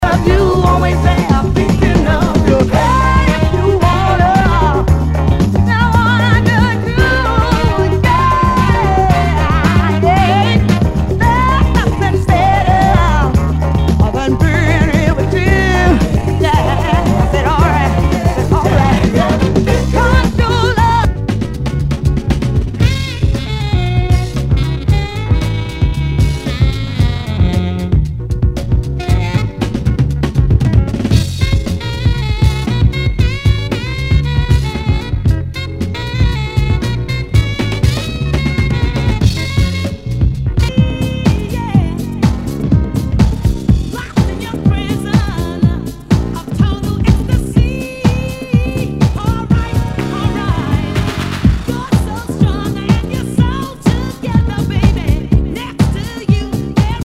SOUL/FUNK/DISCO
ナイス！ダンス・クラシック / アシッド・ジャズ！